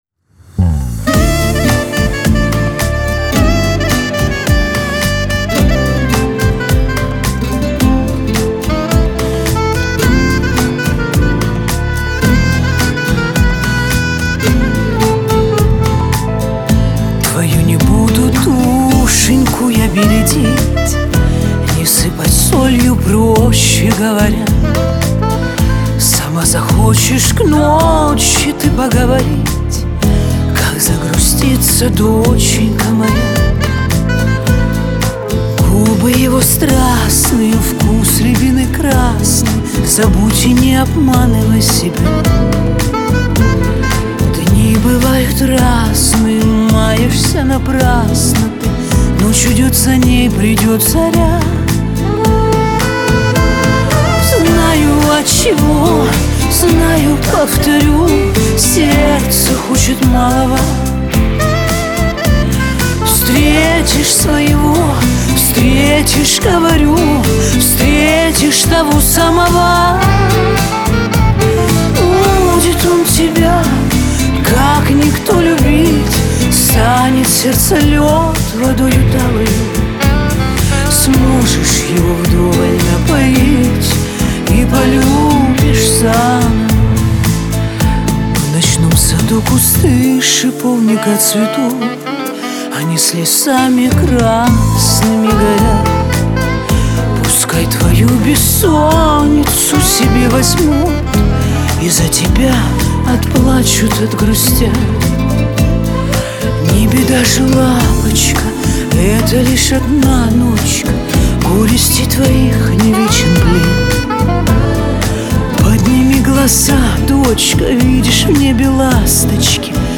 Шансон , Лирика
pop